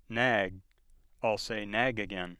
Length differences associated with voiced and voiceless final stop consonants
Spoken in an American voice